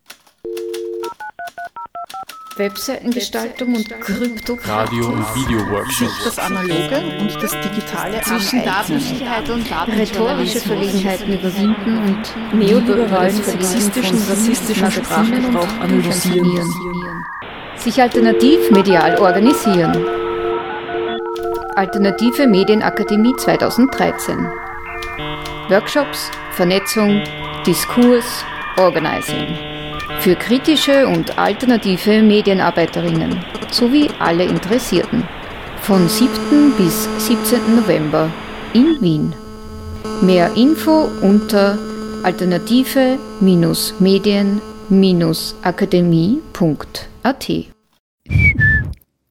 Erster AlMA-Radiojingle
Zur Ankündigung der Alternativen Medienakademie 2013 in Freien Radios und allen Medien, die sich zur Verbreitung von Tönen eignen, gibt es nun den ersten Audio-Jingle.